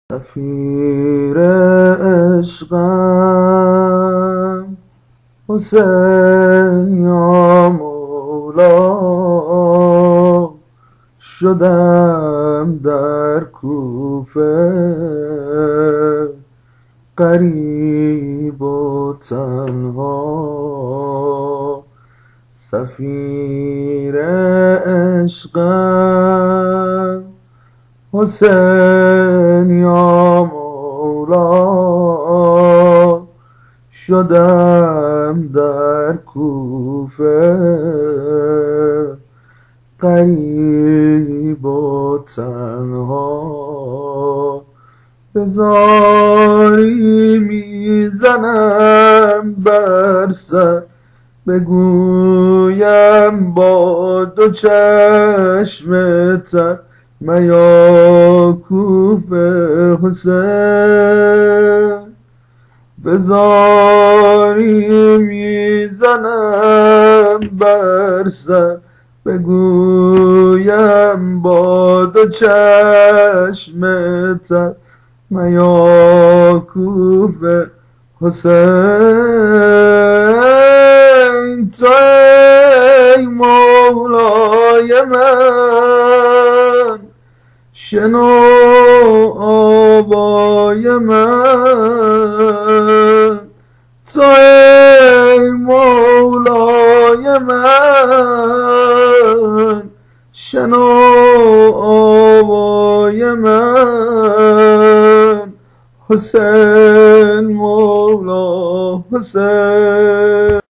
عرض ادب و احترام چهارمین سبک زنجیره ای ویژه ایام محرم که بصورت موضوعی در اختیارتون قرار میگیره!
نوای گرم مداح اهل بیت